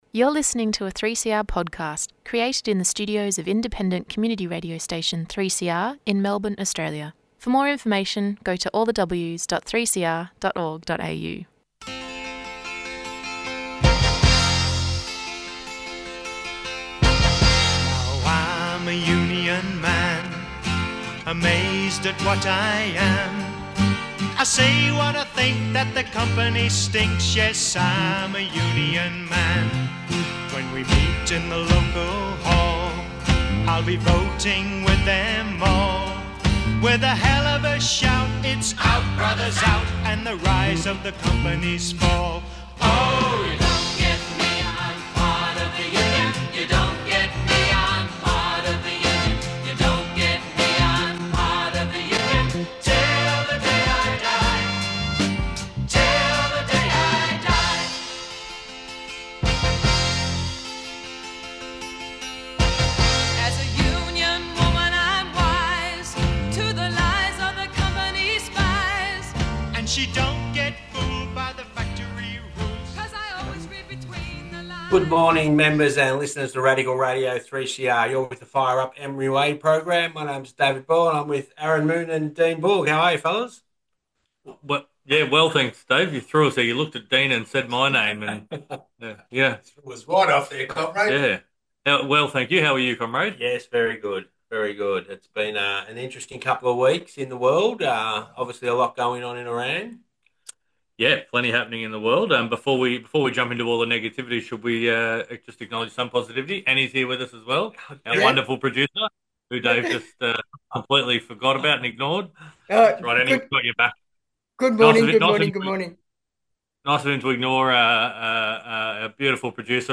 Discussion about Australia's fuel security crisis which has been on the Union's agenda long before the most recent US/Israeli aggression against Iran.